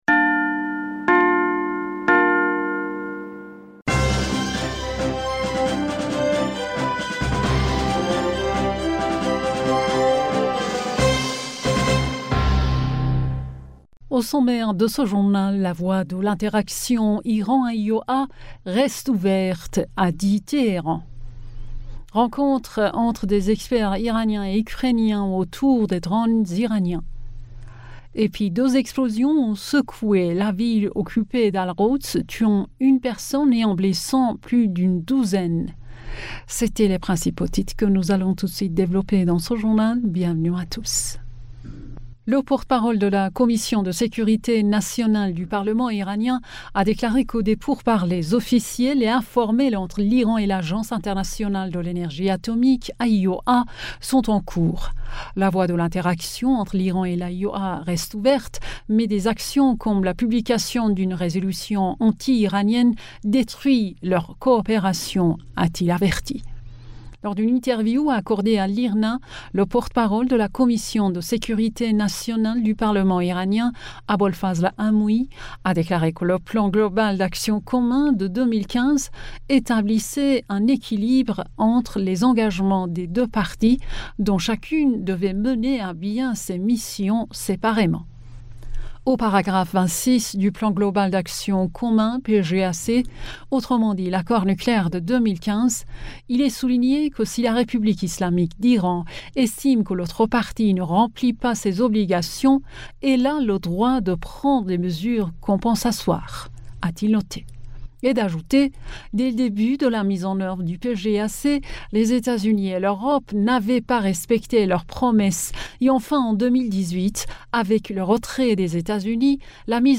Bulletin d'information du 23 Novembre